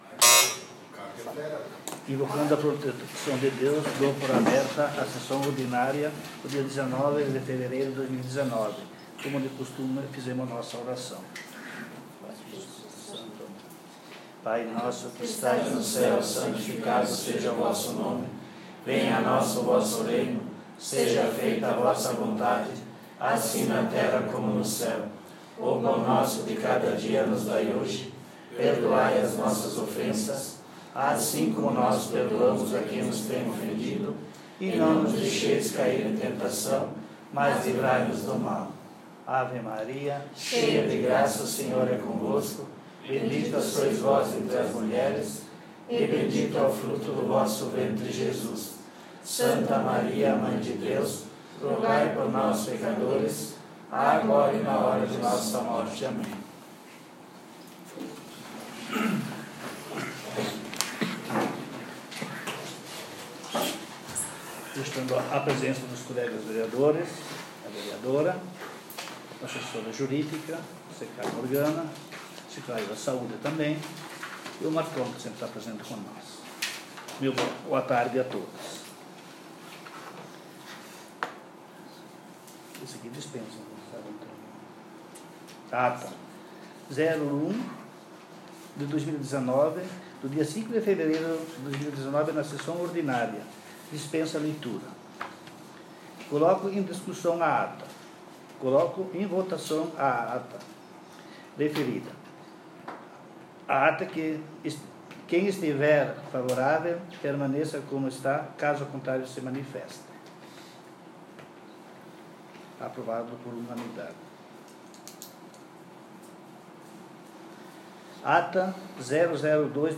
Sessão Ordinária dia 19/02